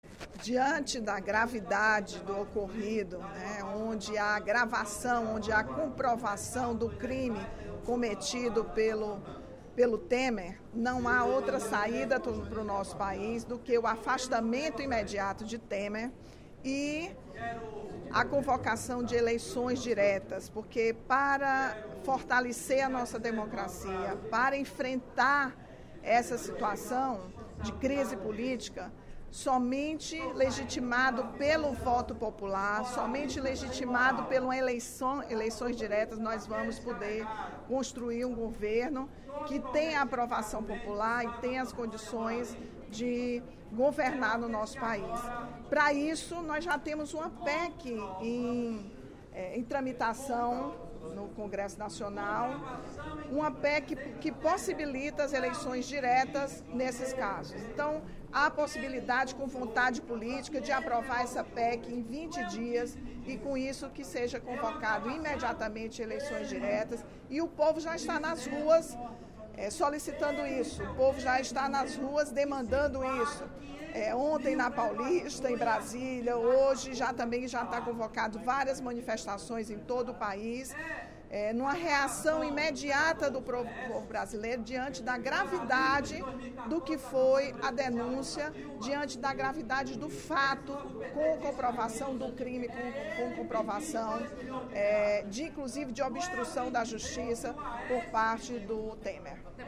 A deputada Rachel Marques (PT) avaliou, durante o primeiro expediente da sessão plenária desta quinta-feira (18/05), a delação feita pelos donos do frigorífico JBS e revelada na noite de quarta-feira (17/05).